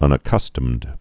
(ŭnə-kŭstəmd)